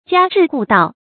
家至戶到 注音： ㄐㄧㄚ ㄓㄧˋ ㄏㄨˋ ㄉㄠˋ 讀音讀法： 意思解釋： 到每家每戶；遍及每家每戶。